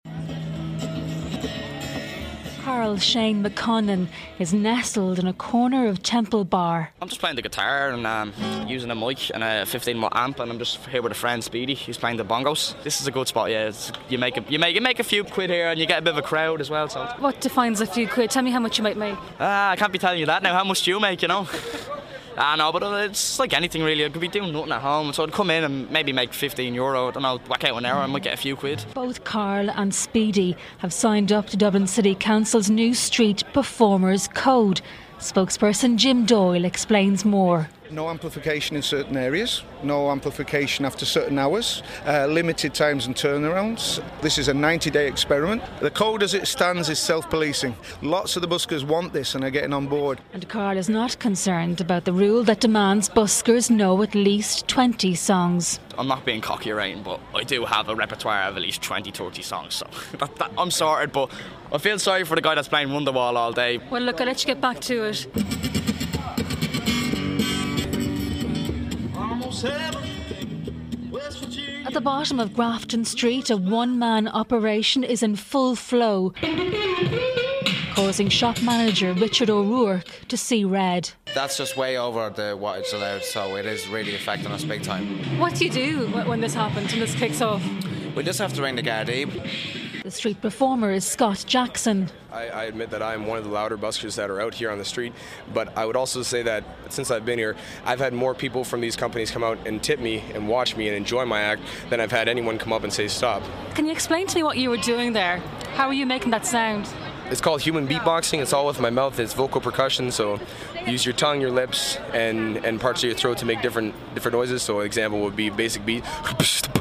(Broadcast on Good Morning Ulster August 3)